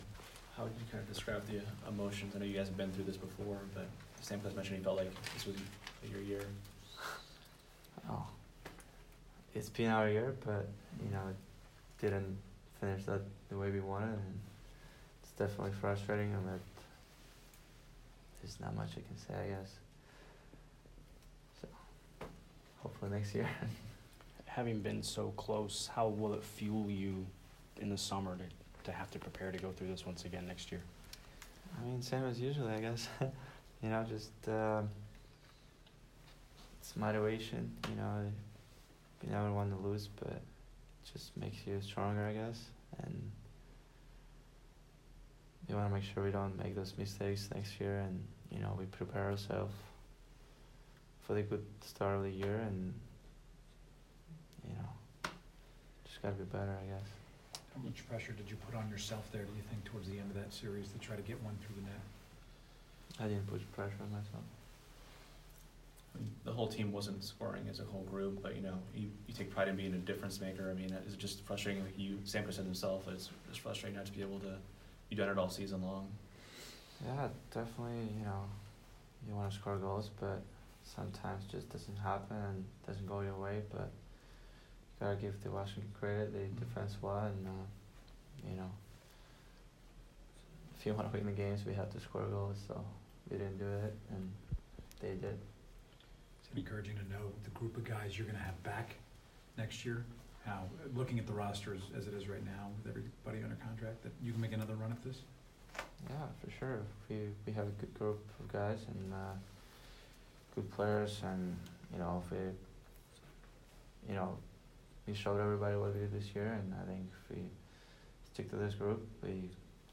Nikita Kucherov Exit Interview 5/24